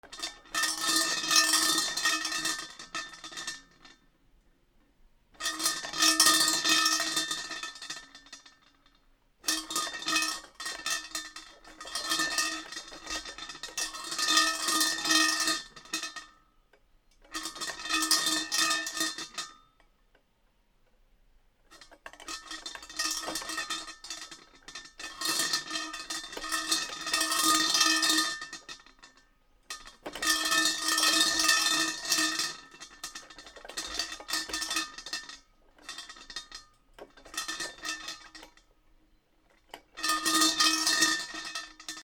鐘
/ G｜音を出すもの / G-10 和_寺社仏閣
お地蔵さん MKH816